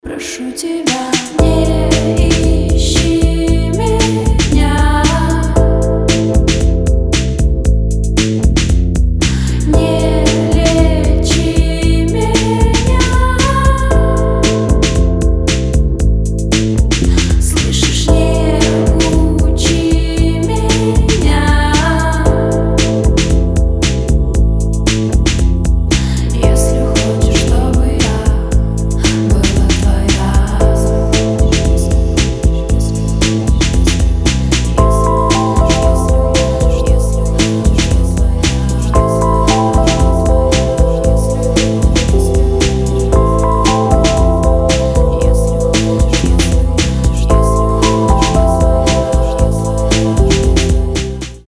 женский вокал
атмосферные
медленные
indie pop
Ambient
Инди-поп